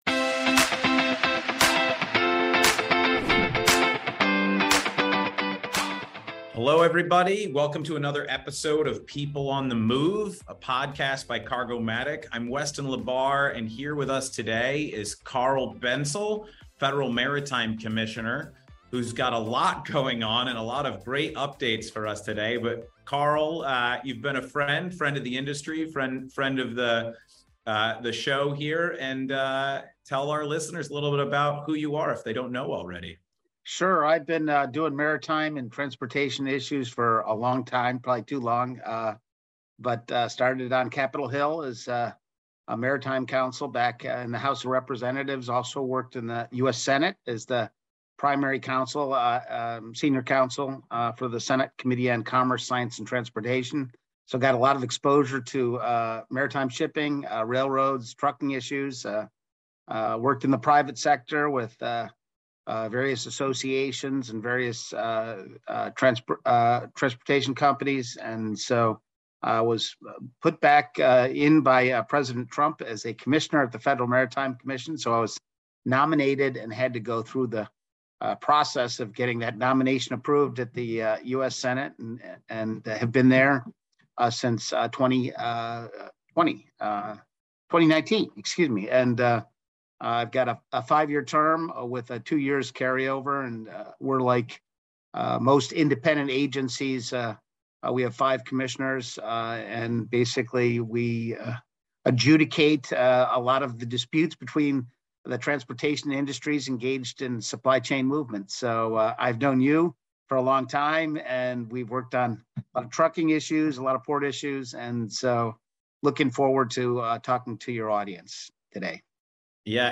People on the Move by Cargomatic - People on the Move: A Cargomatic Podcast With Federal Maritime Commissioner Carl Bentzel